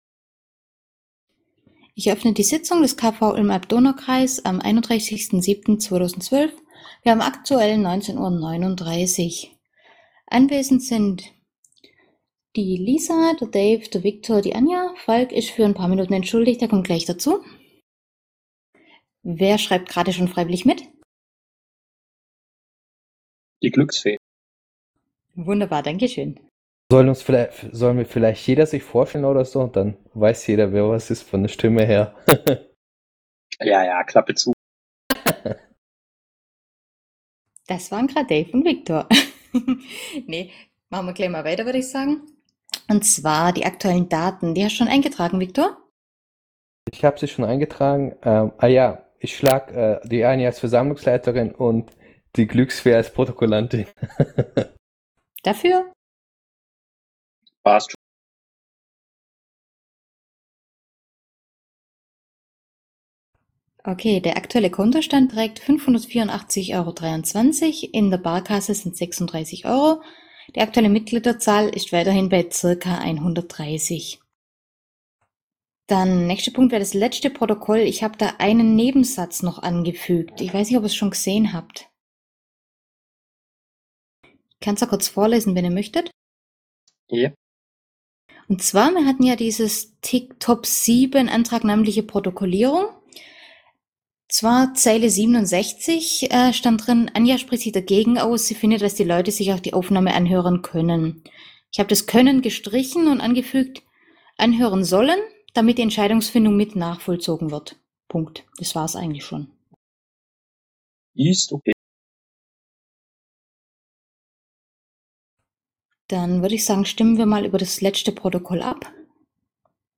Vorstandssitzung des KV Ulm/Alb-Donau-Kreis
Protokoll vom 31.07.2012 | Audiomitschnitt (.ogg)